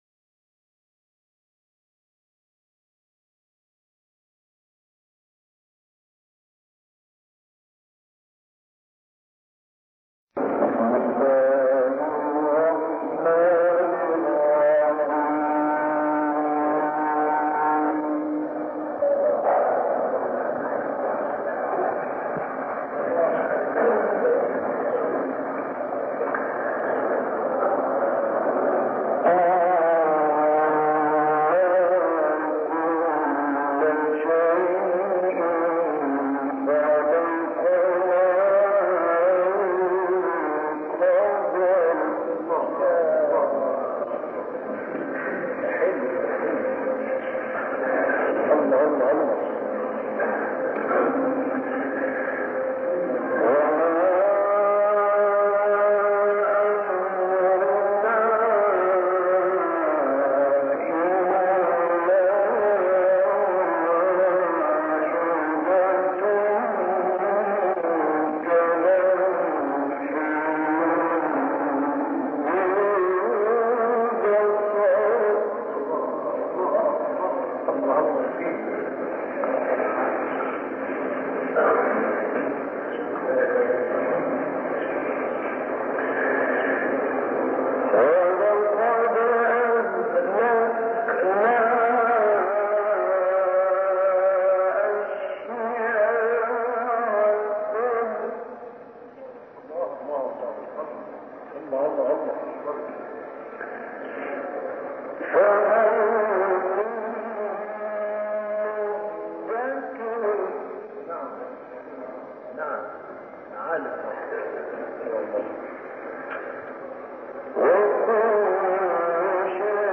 تلاوت «غلوش» در دهه هشتاد میلادی
گروه شبکه اجتماعی: تلاوت کمتر شنیده شده از راغب مصطفی غلوش که در دهه هشتاد میلادی اجرا شده است، ارائه می‌شود.
این تلاوت کمتر شنیده شده در دهه هشتاد میلادی در کشور مصر اجرا شده و مدت زمان آن 30 دقیقه است.